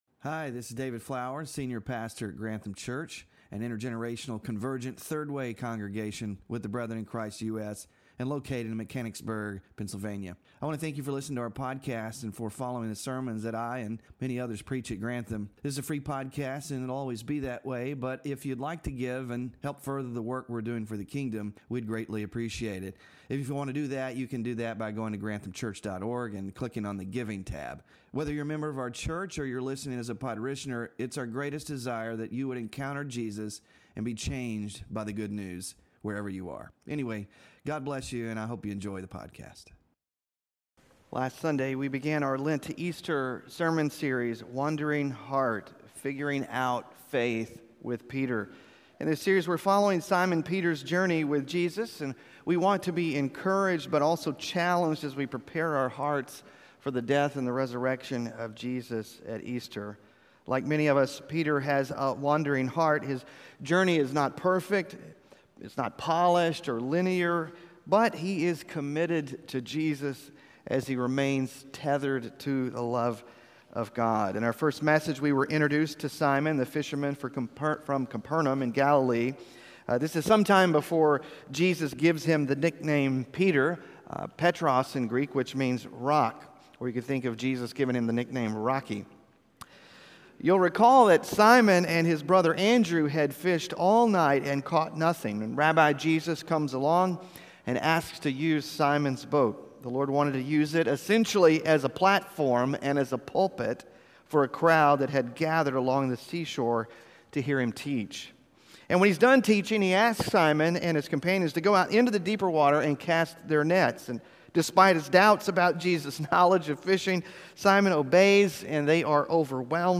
Sermon Focus: The disciples are in a fishing boat on the lake when a storm hits.